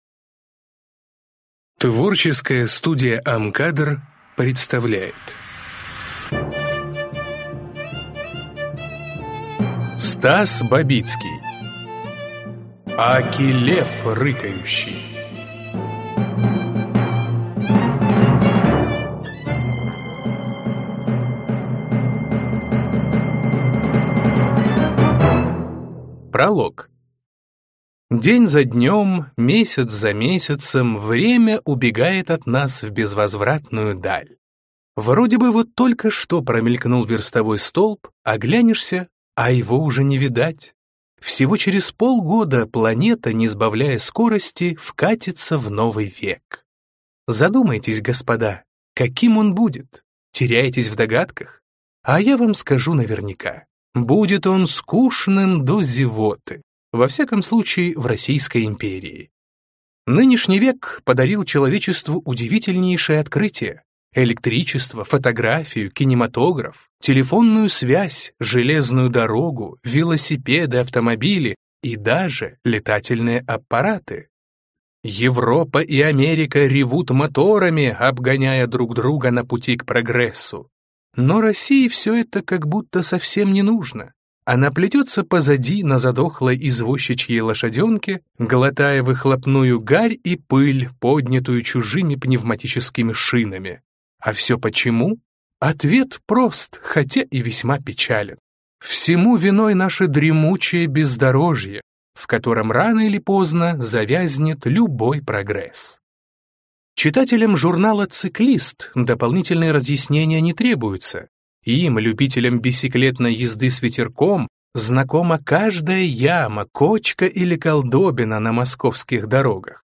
Аудиокнига Аки лев рыкающий | Библиотека аудиокниг